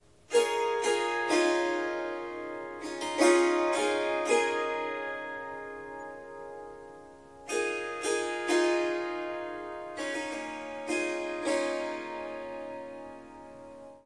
Swarmandal印度竖琴曲谱 " 竖琴坚定弹奏曲谱2
这个奇妙的乐器是Swarmandal和Tampura的结合。
它被调到C sharp，但我已经将第四个音符（F sharp）从音阶中删除了。
一些录音有一些环境噪音（鸟鸣，风铃）。